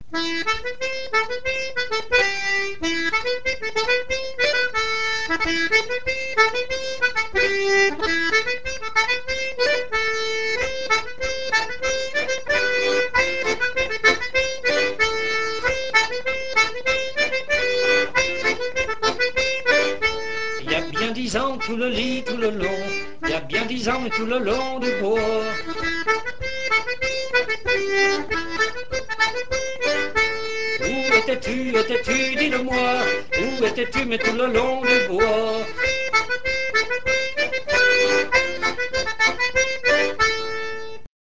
Titre / Title : Tour (An dro)